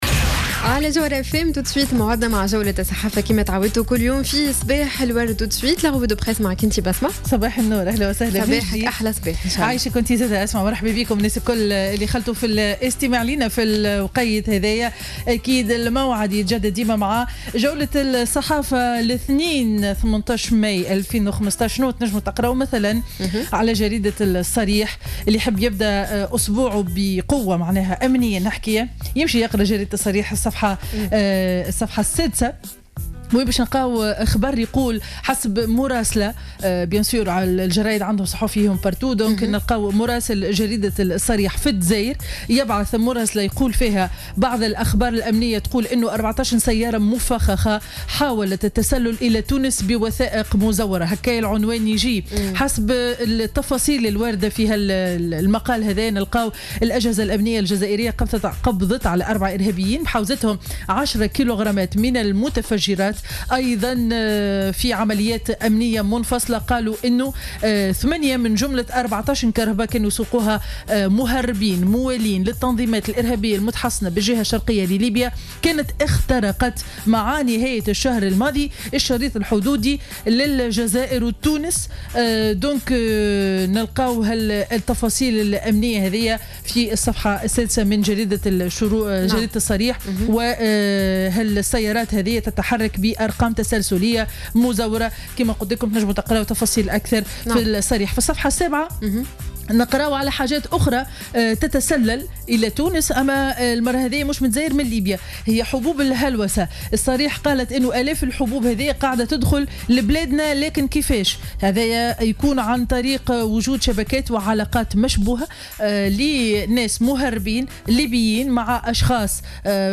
Revue de presse du 18 mai 2015